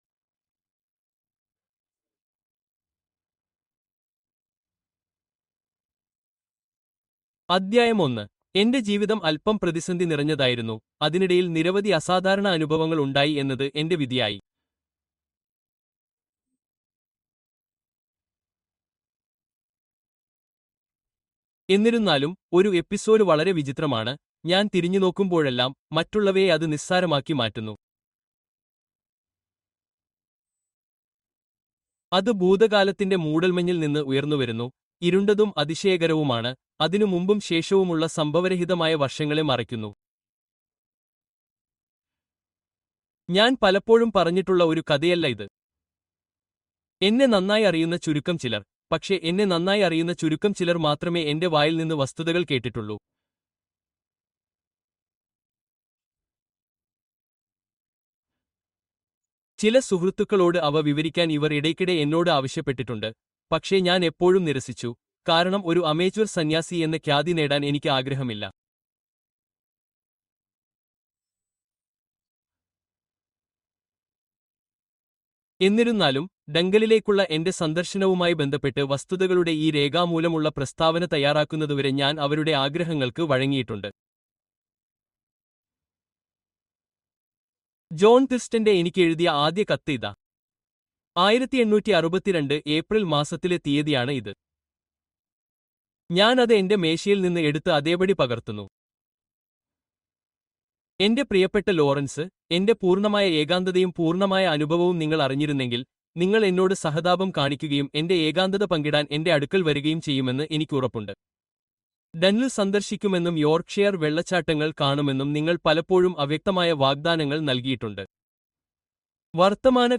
The Queer Feet by G.K. Chesterton - Father Brown Mystery Audiobook